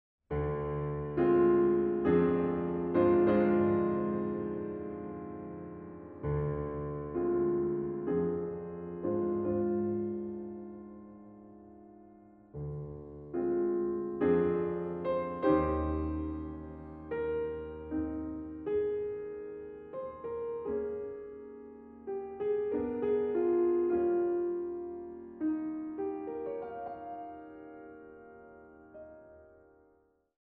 Exquisite sounds well-recorded; excellent booklet.
Piano